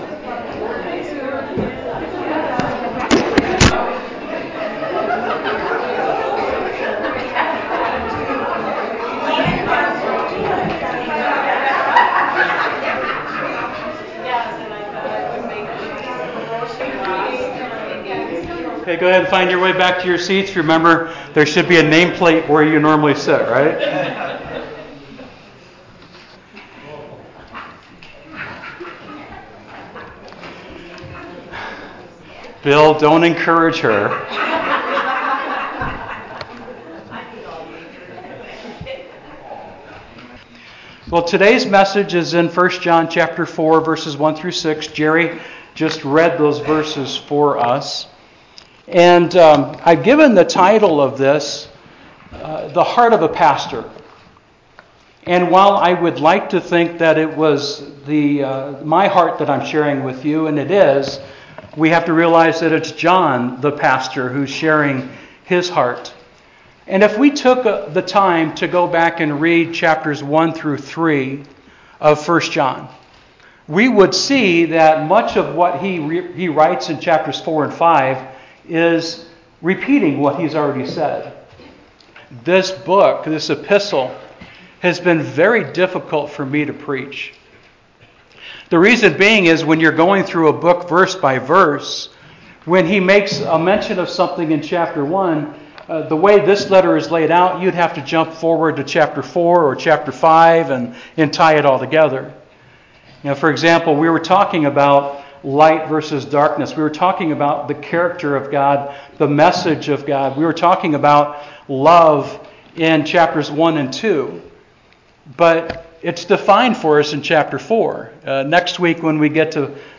Sermon Title: “The Heart of a Pastor”